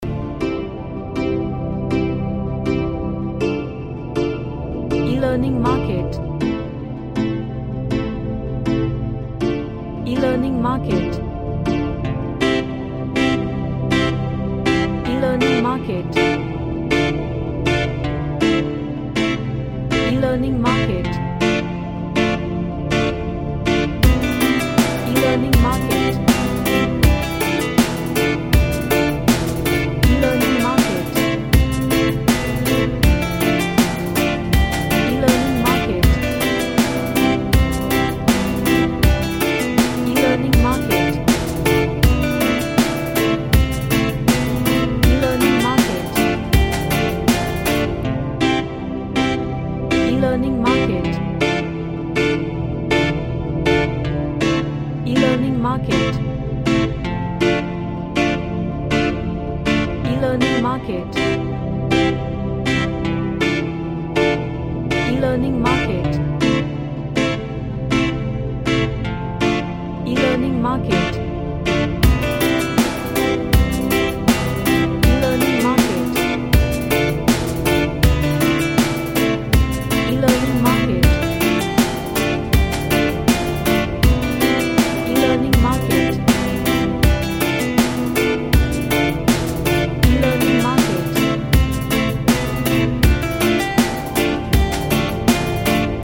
A Reggae track with lots of perccusion and guitar melody.
Happy